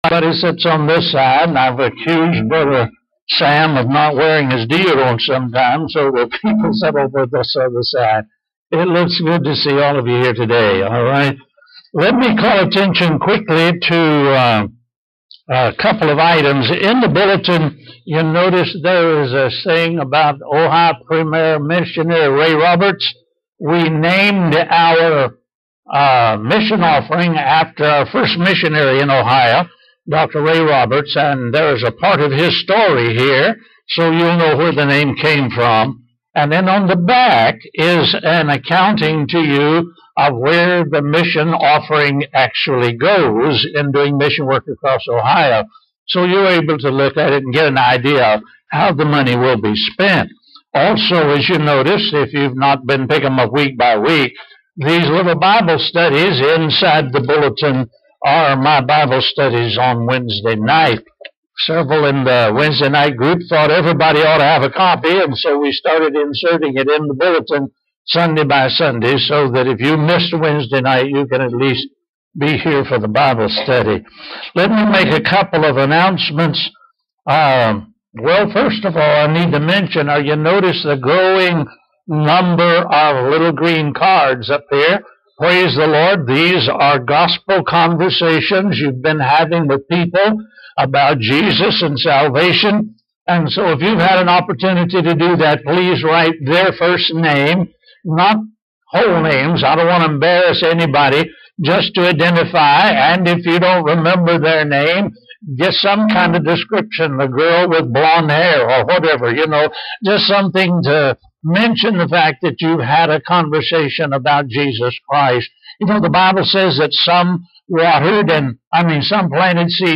Sermons - Hillsdale Baptist Church